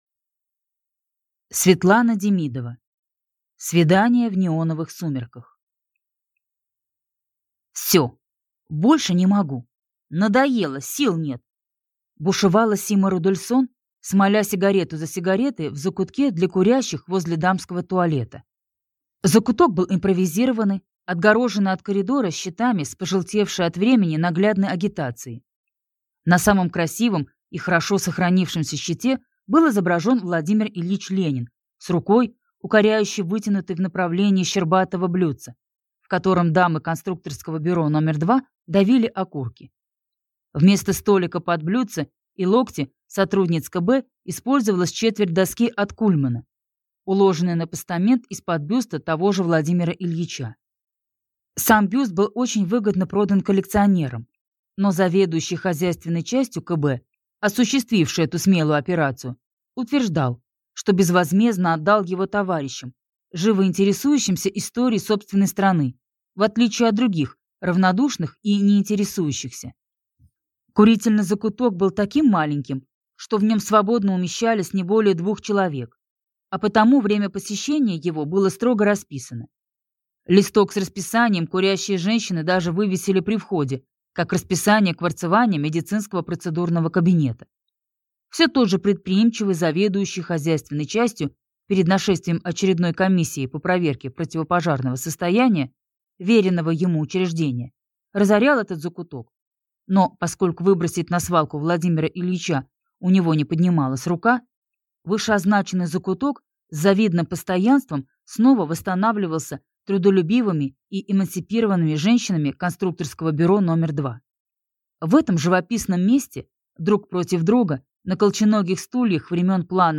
Аудиокнига Свидание в неоновых сумерках | Библиотека аудиокниг